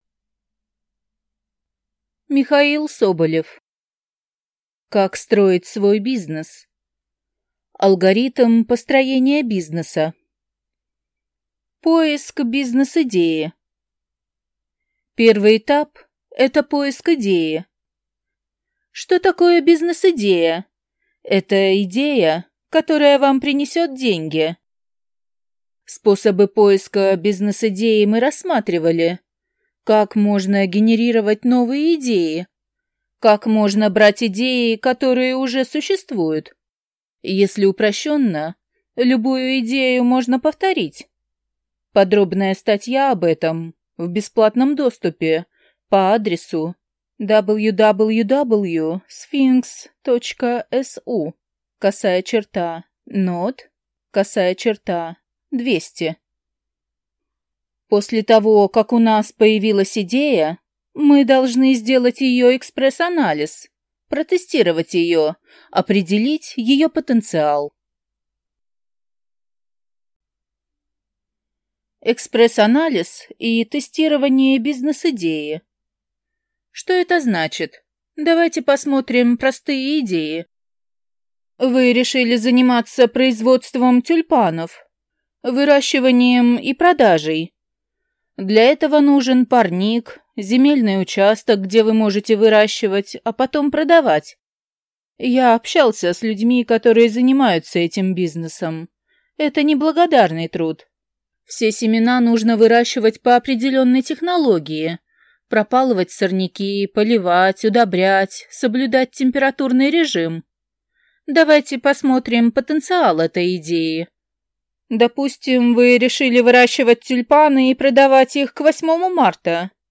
Аудиокнига Как строить свой бизнес. Алгоритм построения бизнеса | Библиотека аудиокниг